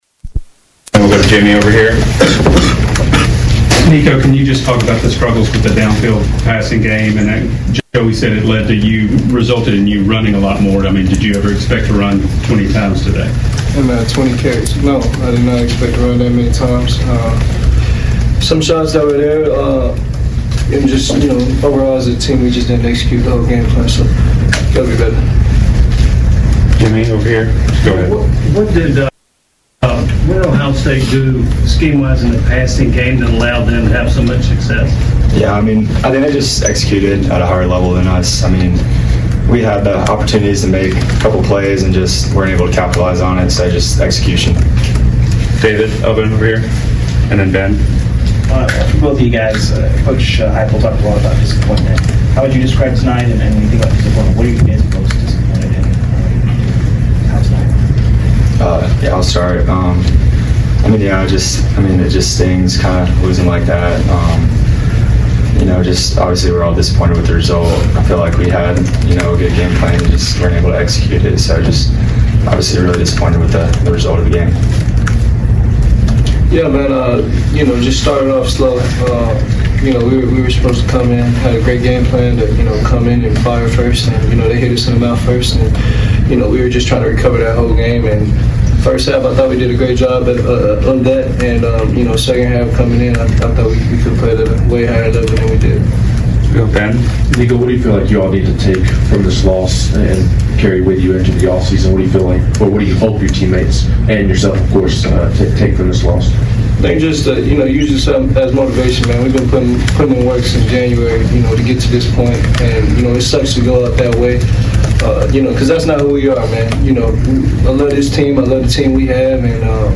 Tennessee Volunteers CFP Postgame